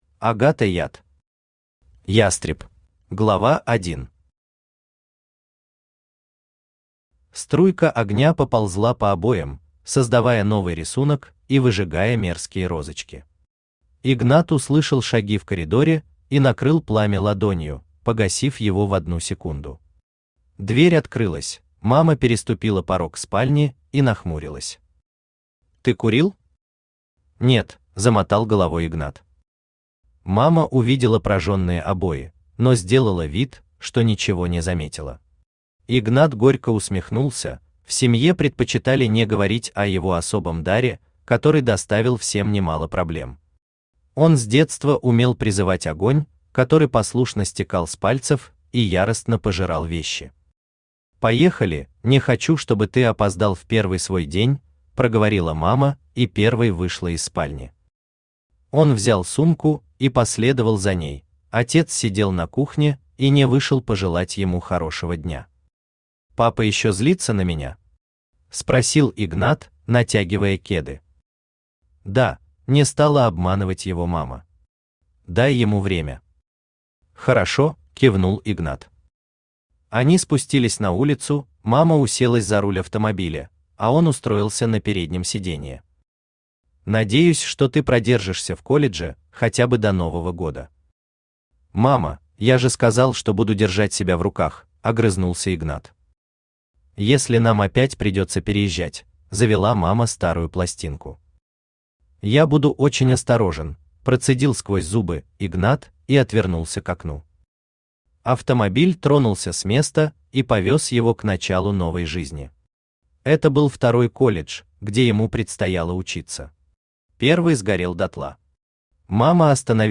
Aудиокнига Ястреб Автор Агата Яд Читает аудиокнигу Авточтец ЛитРес.